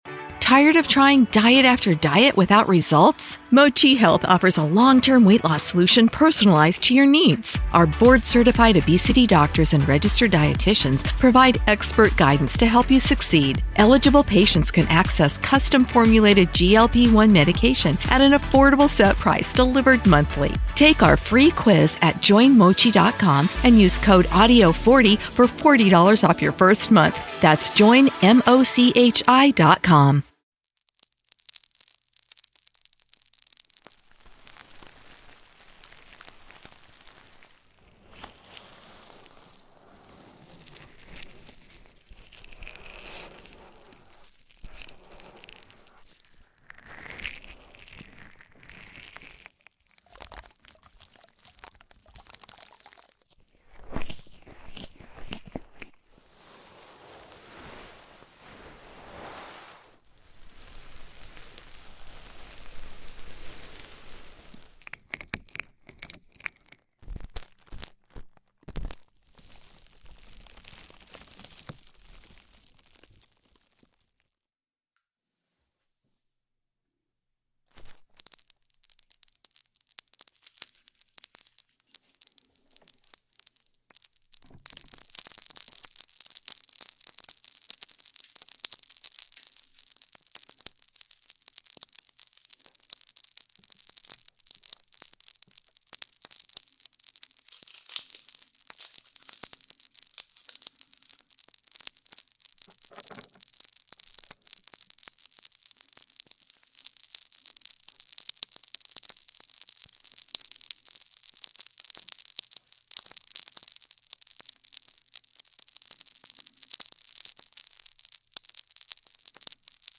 ASMR Sleep Ear Cleaning For Endless Tingles! ( No Talking)